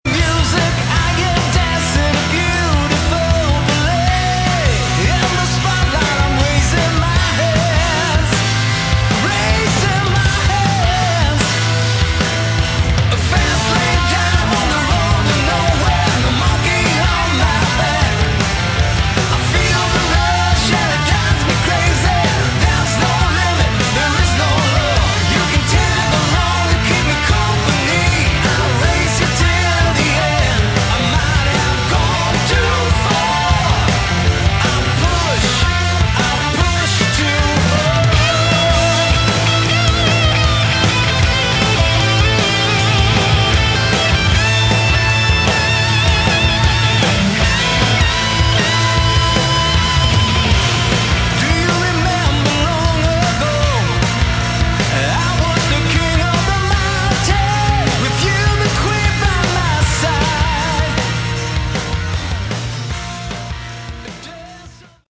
Category: Melodic Hard Rock
lead & backing vocals
lead & rhythm guitar, backing vocals
drums, percussion, backing vocals
organ, keyboards, backing vocals